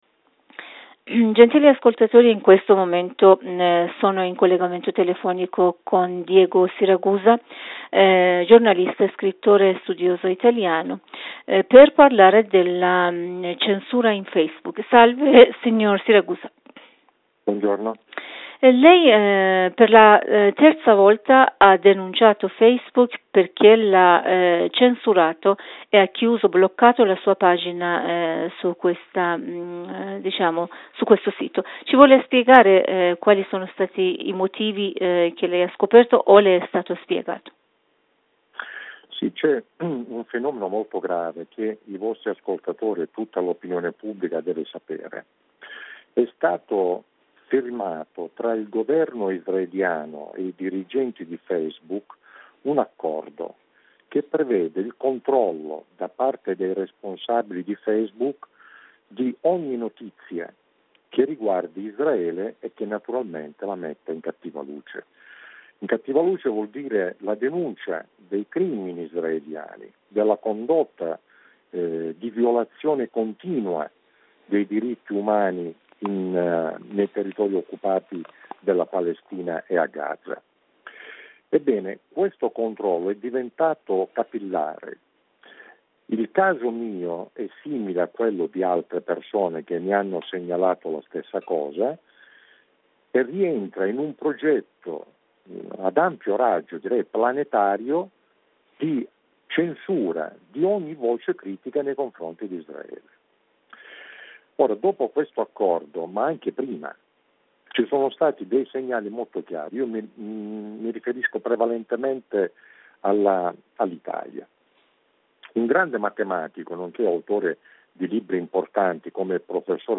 Per ascoltare la versione integrale dell'intervista cliccare qui sopra: